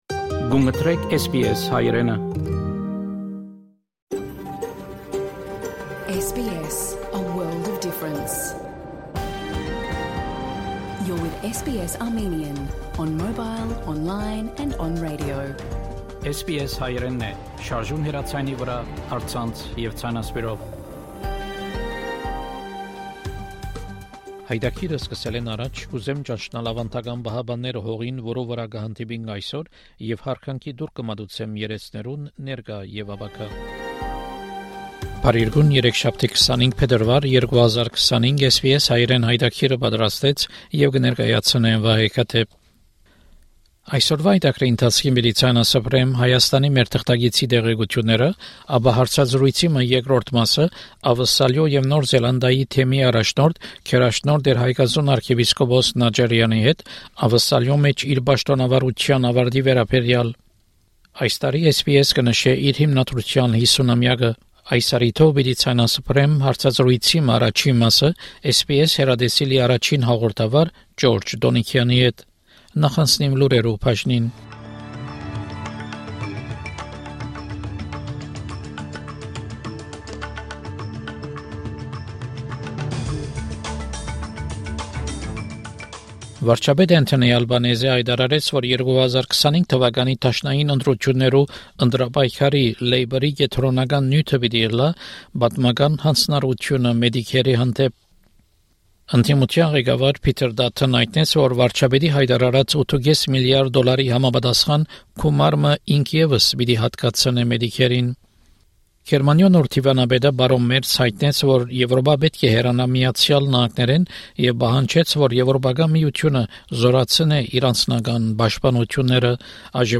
SBS Armenian news bulletin from 18 February 2025 program.